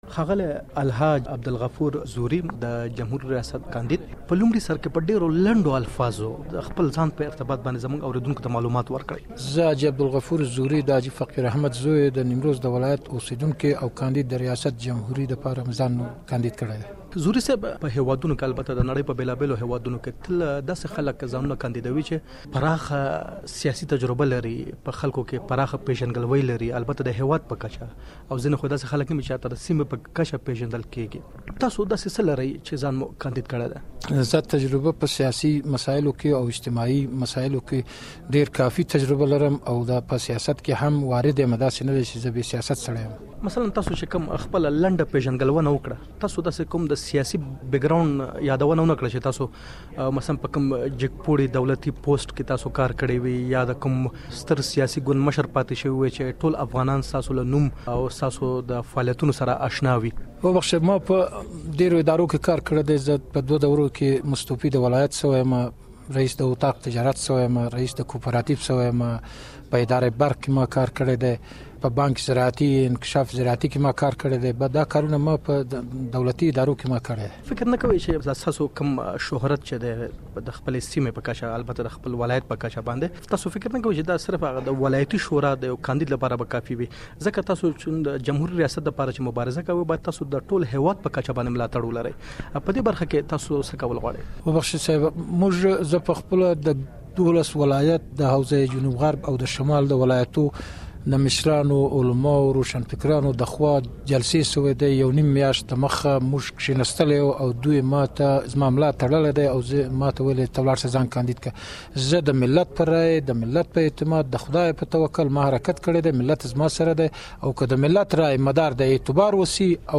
ځانګړې مرکه واورﺉ